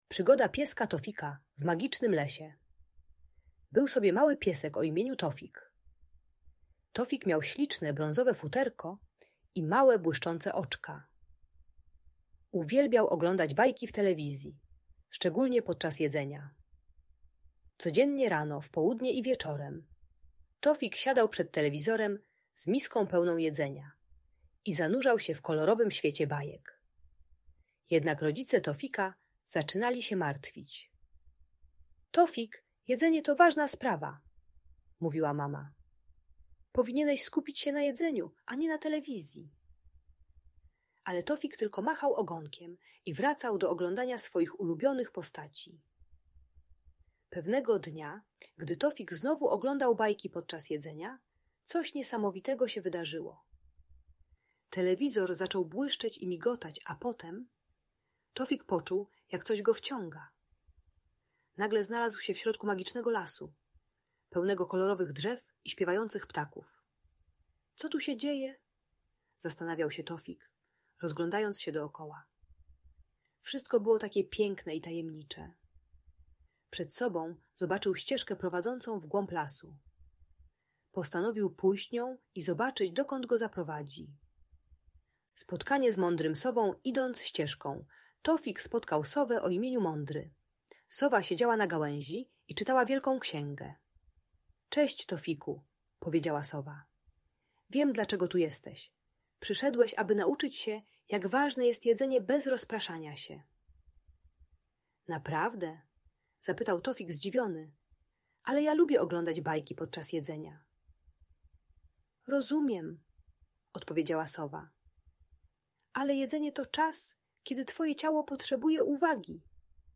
Przygoda Pieska Tofika w Magicznym Lesie - Bajki Elektronika | Audiobajka